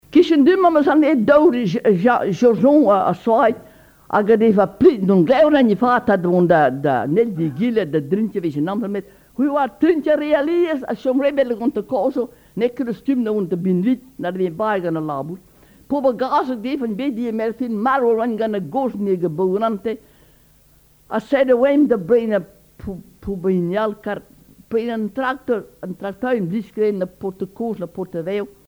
Genre conte
Enquête Dastum
Catégorie Récit